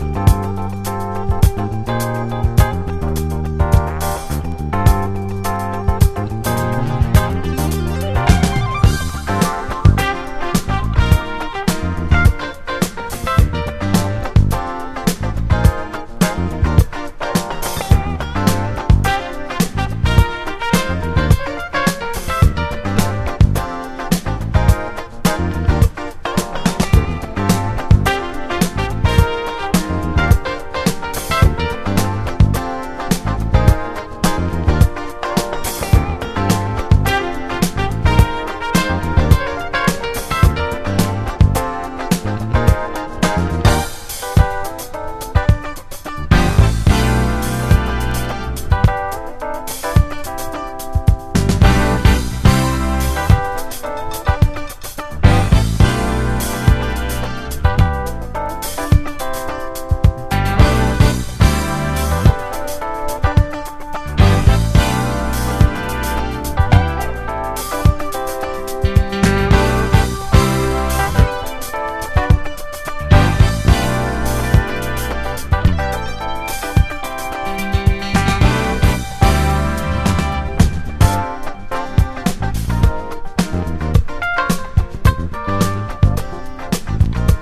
JAZZ / DANCEFLOOR / ACID JAZZ (UK)
ACID JAZZ前夜のジャズで踊るUK JAZZ DANCEシーンを切り取った名作コンピ！